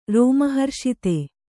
♪ rōma harṣite